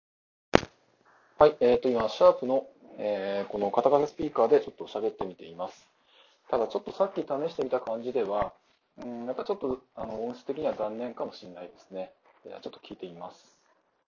AN-SX7Aの内蔵マイクで、そのままiPhoneで録音してみたもの